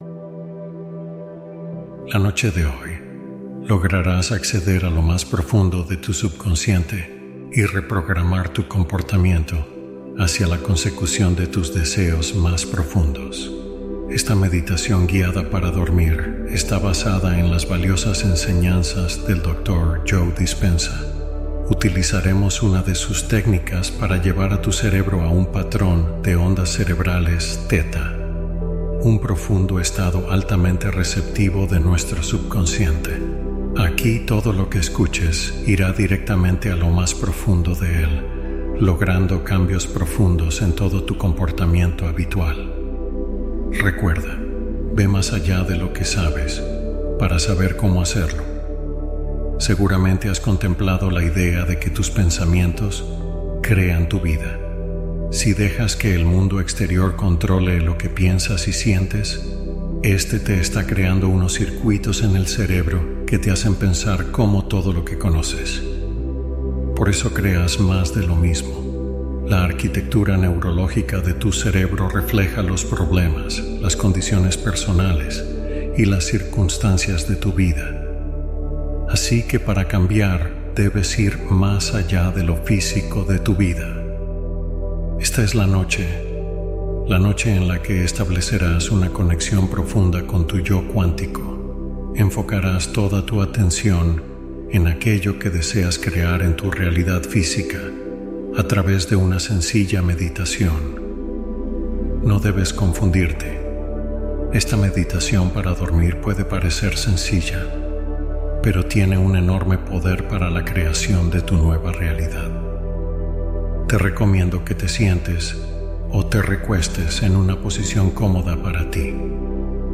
Da un salto cuántico en una noche: hipnosis y afirmaciones